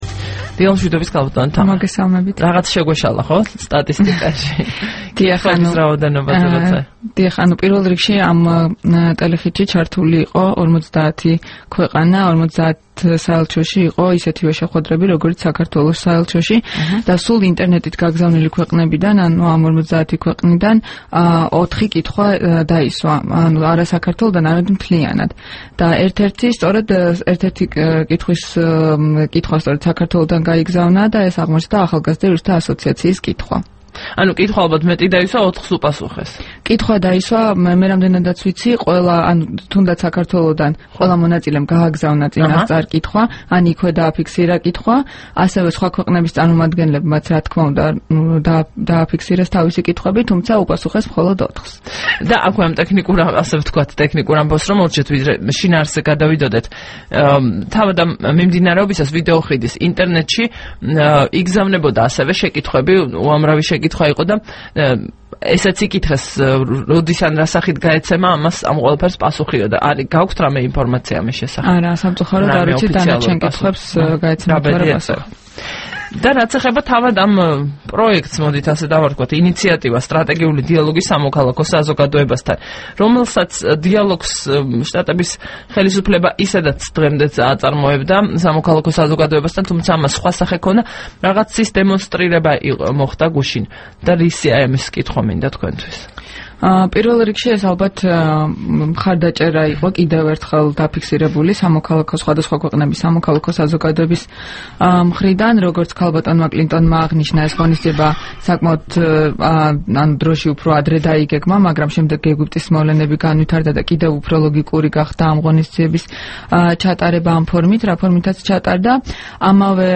საუბარი თამარ ჩუგოშვილთან